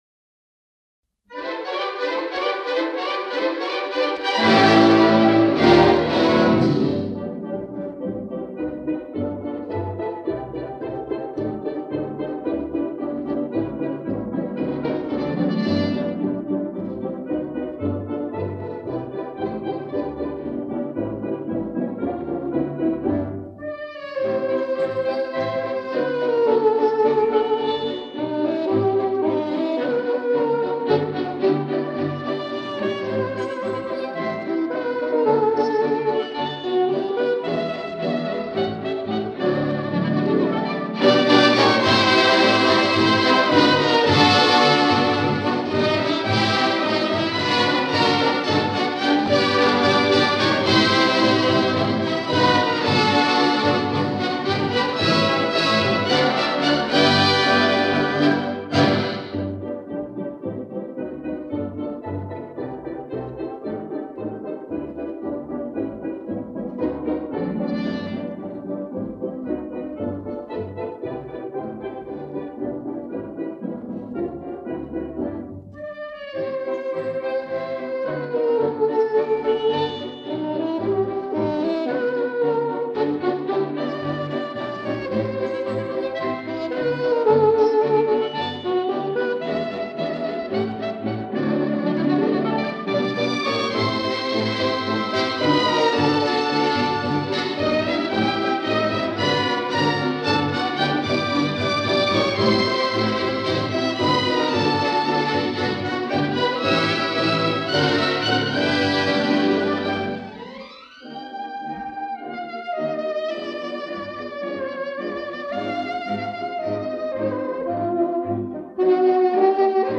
Орк.